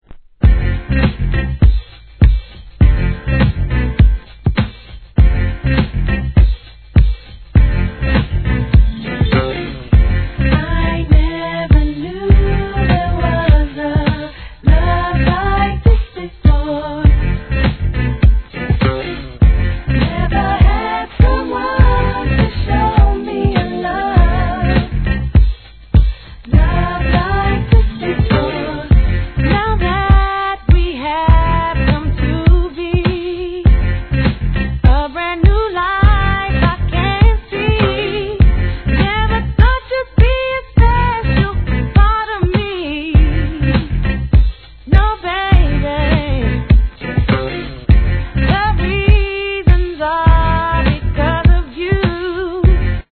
HIP HOP/R&B
彼女の透き通るような歌声で大ヒット!!